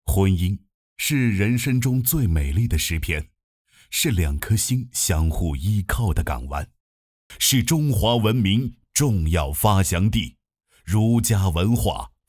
Fängslande Historisk Berättarröst
Fängsla din publik med en djup, resonant AI-röst designad för historiska dokumentärer, episka sagor och pedagogiskt berättande.
Text-till-tal
Noiz.ais historiska berättarröst erbjuder en allvarlig, auktoritativ ton som förflyttar lyssnare tillbaka i tiden.
Denna röst har en naturlig kadens och uttrycksfull intonation speciellt anpassad för långformat berättande.
Med subtila variationer i ton och tempo speglar den nyanserna hos en professionell dokumentärberättare.